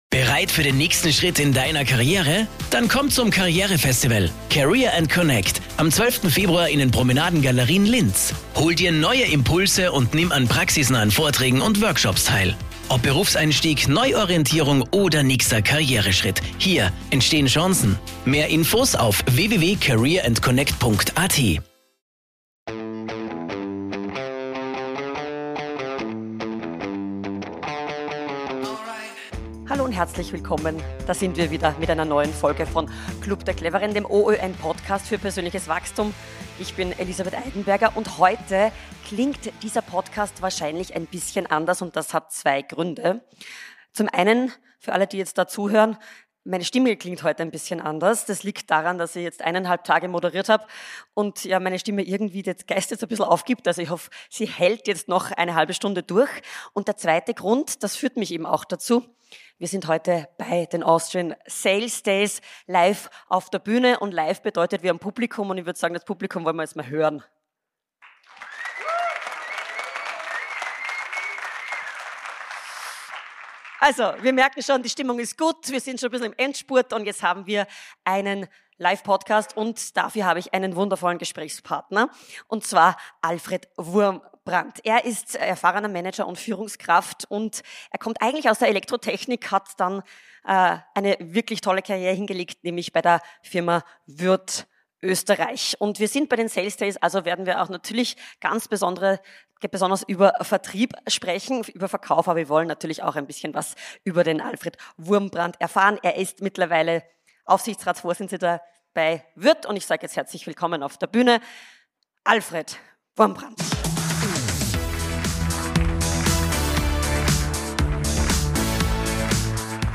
Im Live-Podcast bei den Austrian Sales Days